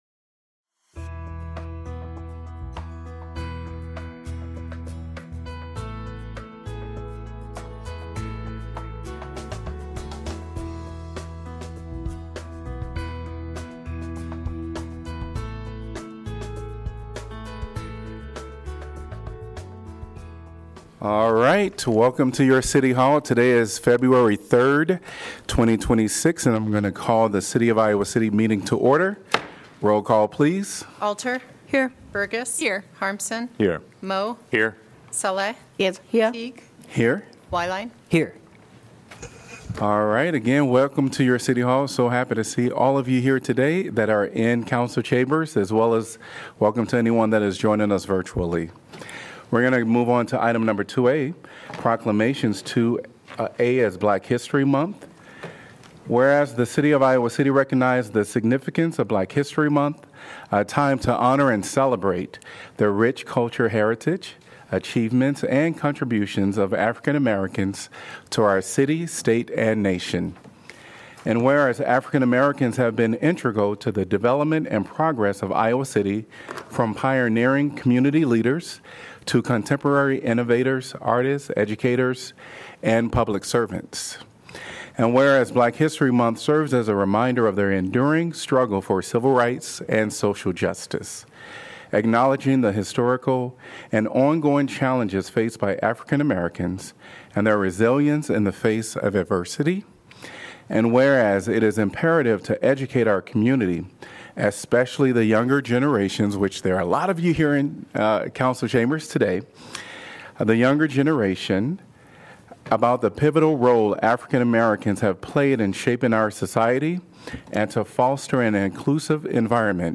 Gavel-to-gavel coverage of the Iowa City City Council meeting, generally scheduled for the first and third Tuesday of each month.